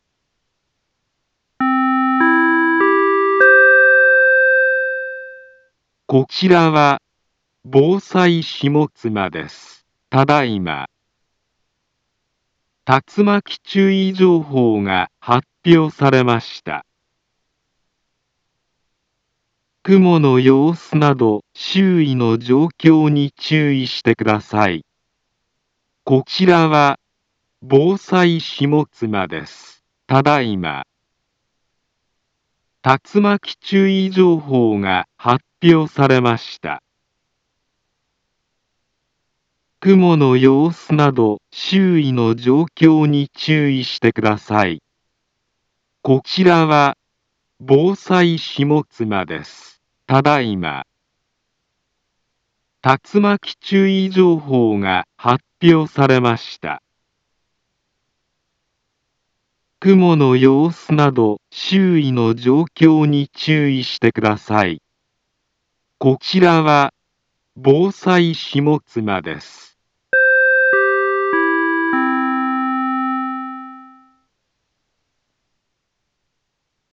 Back Home Ｊアラート情報 音声放送 再生 災害情報 カテゴリ：J-ALERT 登録日時：2021-07-11 15:55:06 インフォメーション：茨城県北部、南部は、竜巻などの激しい突風が発生しやすい気象状況になっています。